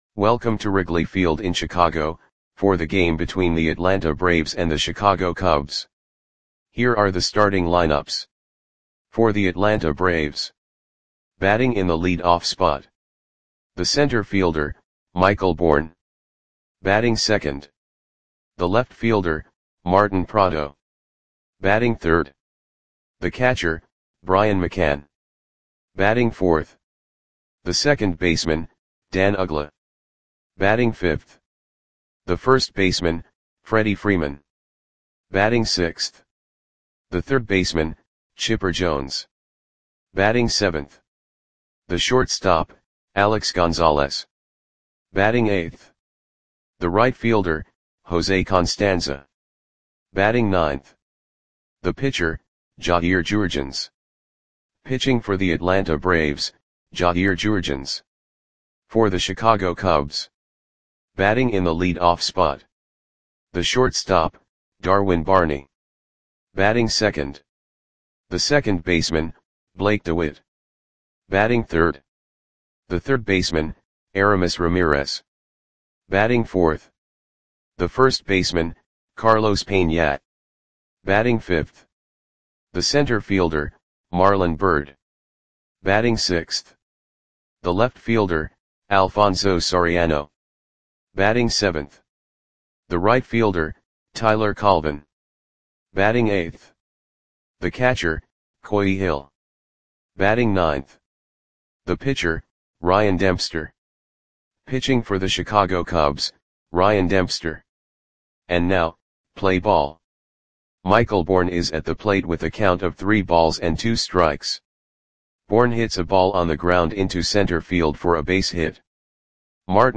Audio Play-by-Play for Chicago Cubs on August 22, 2011
Click the button below to listen to the audio play-by-play.